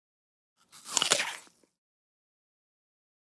DINING - KITCHENS & EATING APPLE: INT: Single detailed bite into an apple.